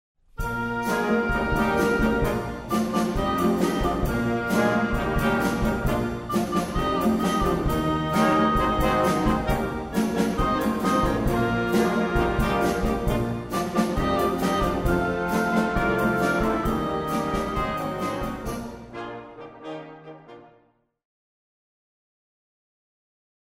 Youth Wind Band